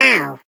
Sfx_tool_spypenguin_vo_hit_wall_09.ogg